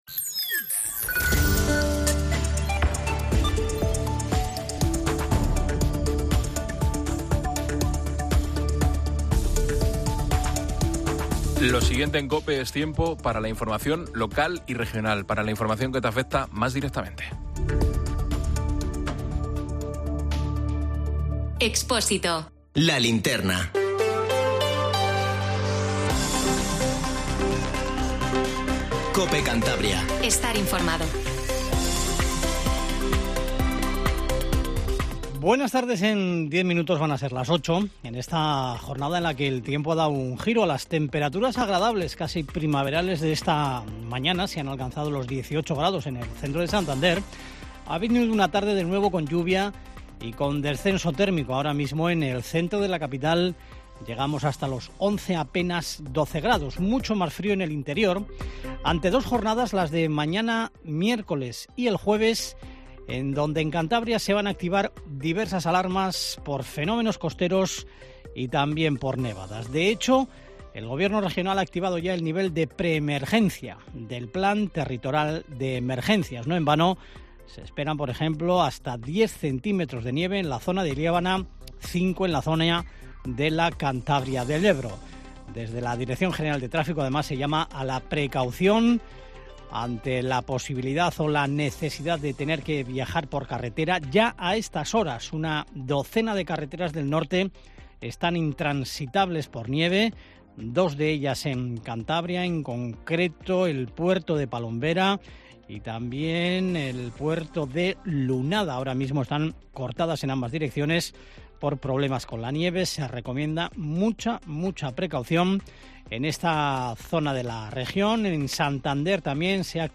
AUDIO: Informativo Tarde COPE CANTABRIA